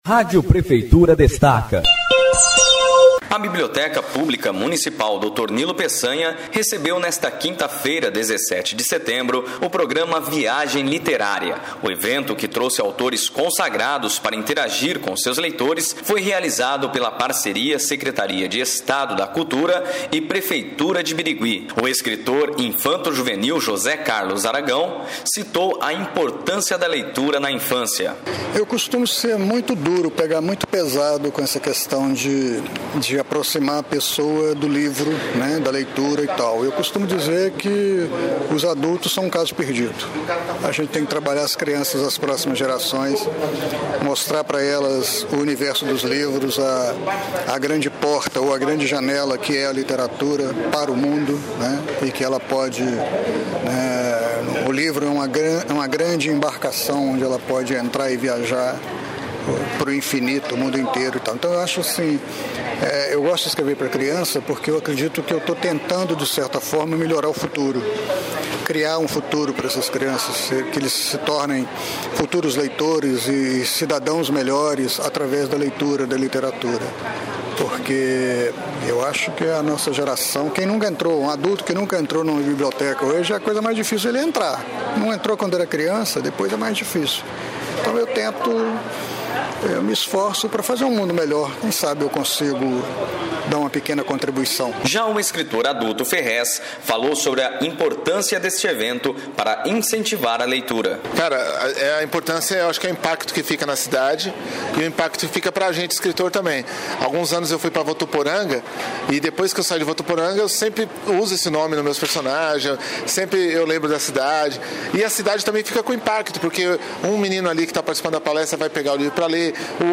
A Rádio Prefeitura falou com os escritores